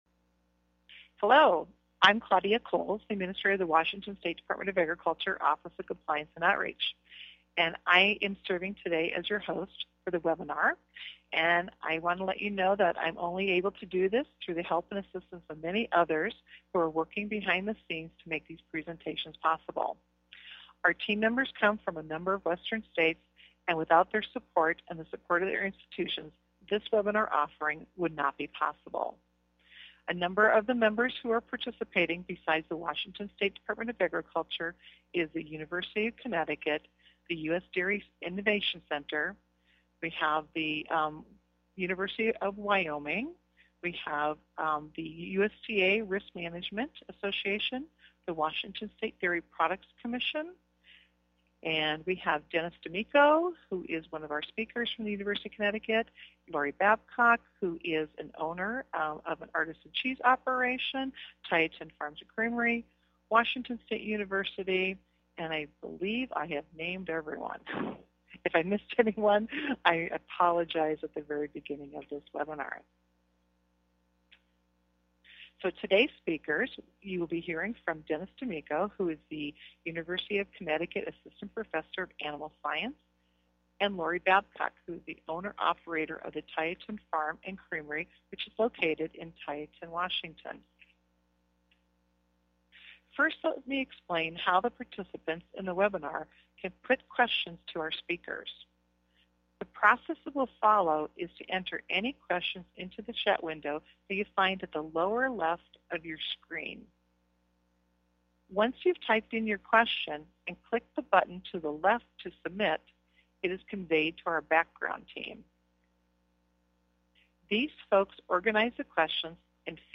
Webinar Opening Comments - 4 minutes